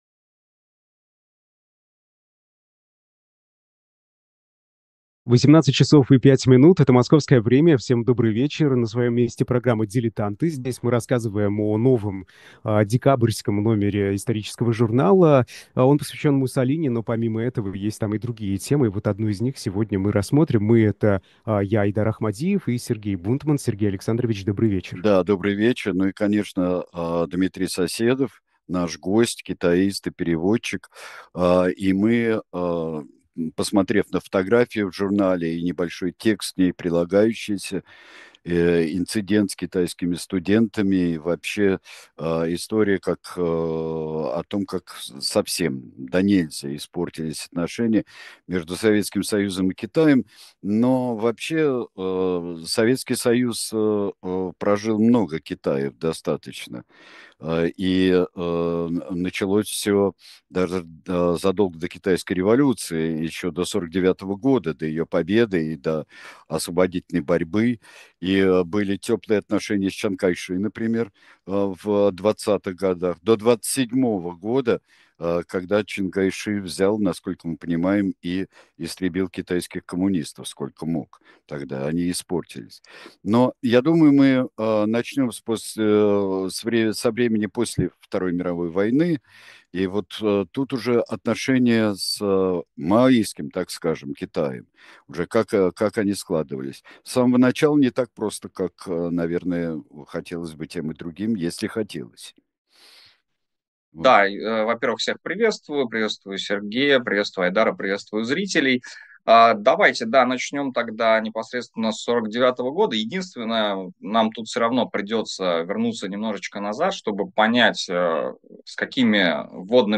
востоковед-китаист, переводчик